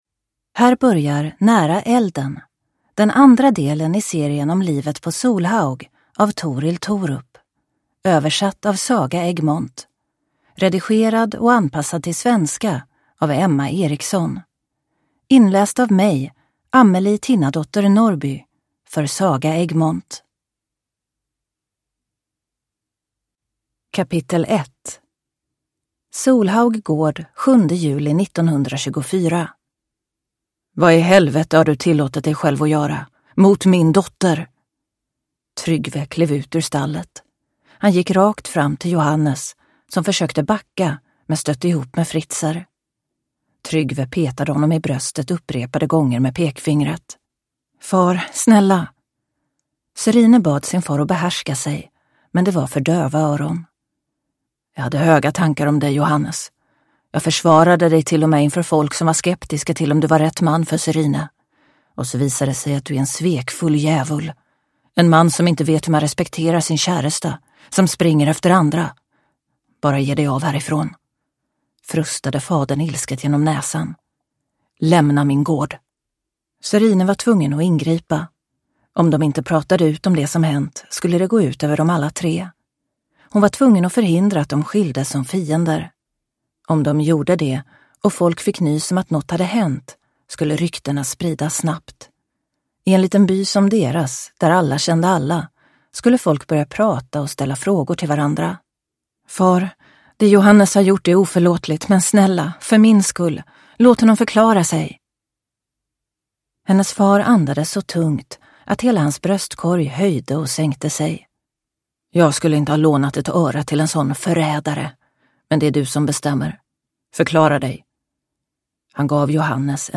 Nära elden (ljudbok) av Torill Thorup